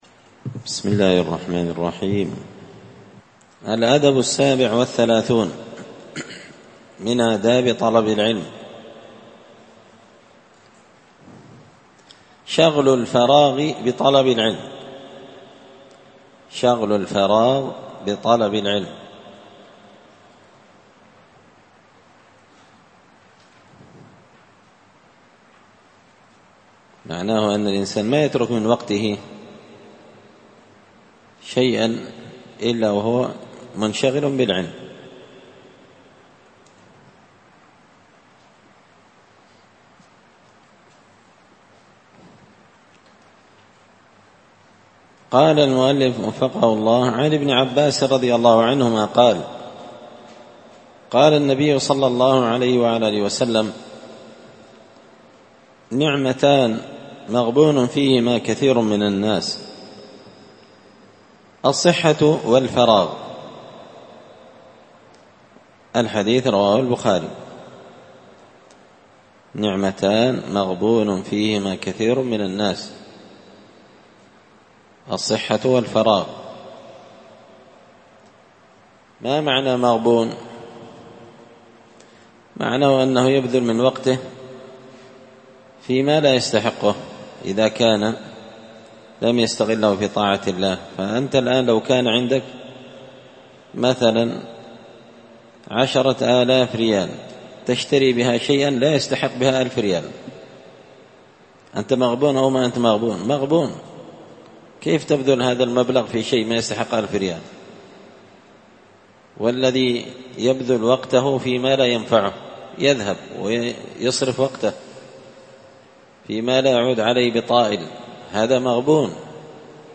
الدرس الخامس والأربعون (45) الأدب السابع والثلاثون شغل الفراغ بطلب العلم